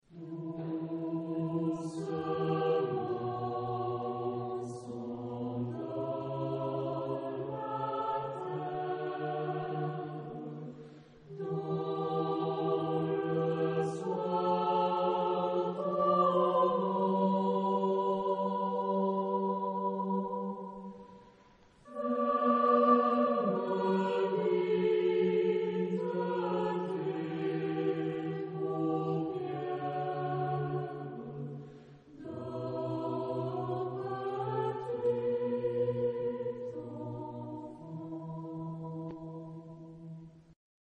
Genre-Style-Forme : Populaire ; Profane ; Berceuse
Type de choeur : SAH  (3 voix mixtes )
Tonalité : mi mineur